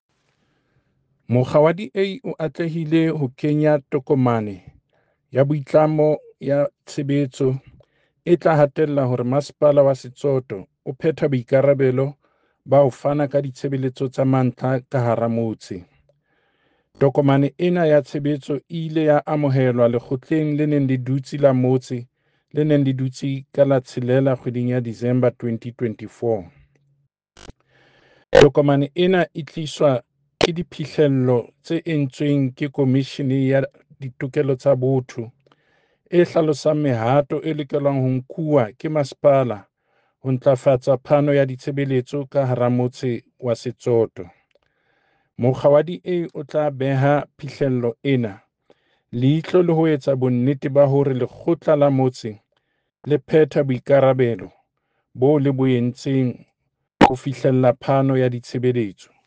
Sesotho soundbite by David Masoeu MPL